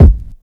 TAX - Boomin Kick.wav